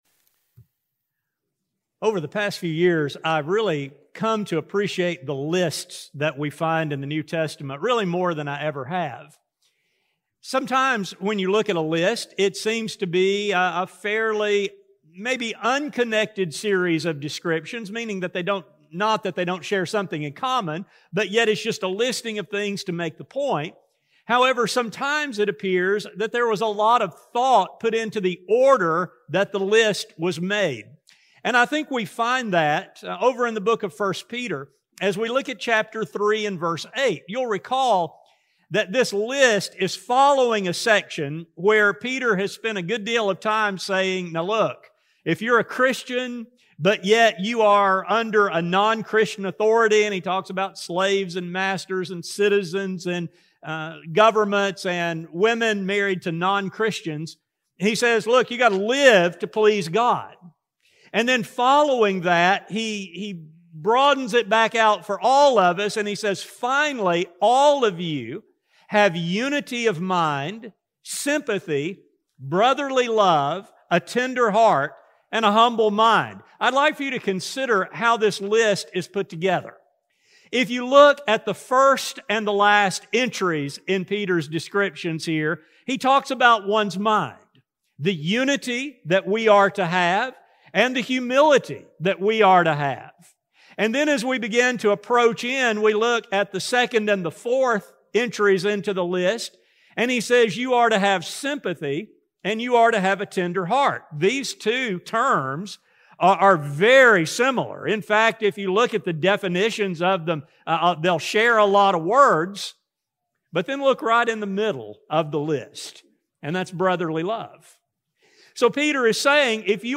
This study explores the significance of God’s compassion and His expectations for His children to show this divine quality as well. Recording of a sermon